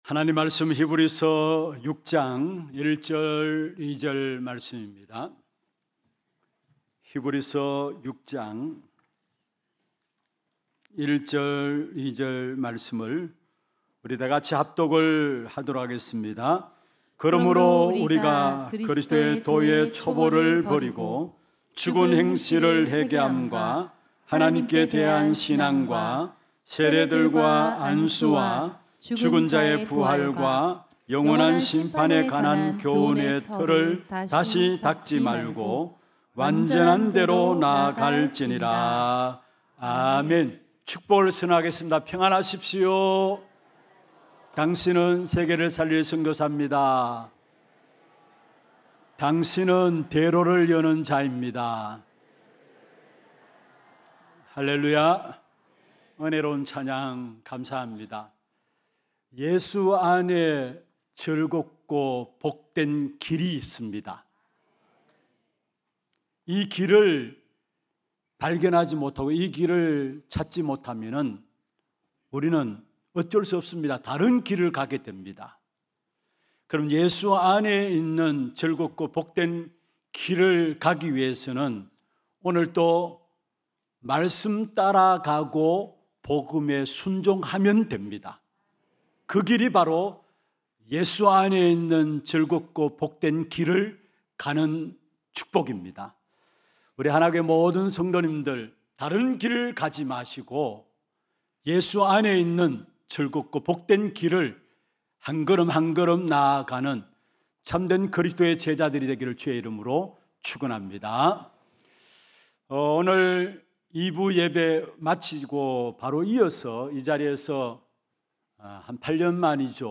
2026년 4월 19일 주일 1부 예배